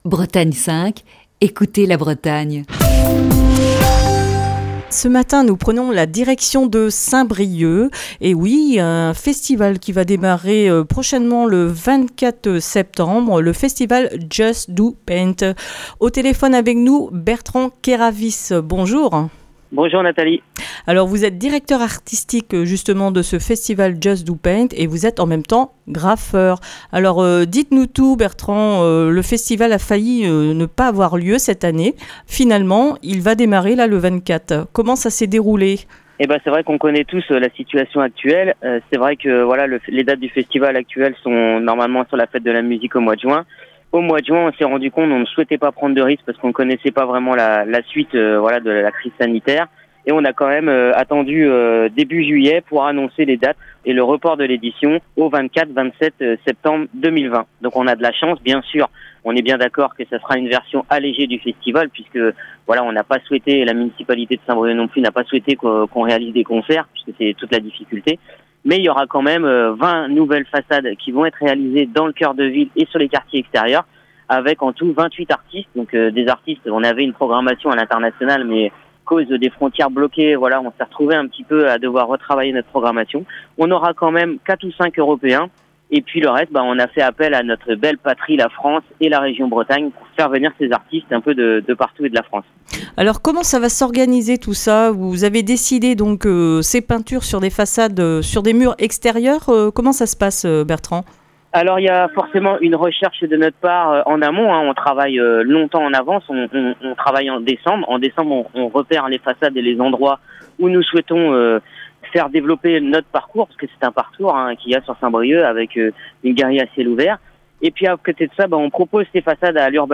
Elle est au téléphone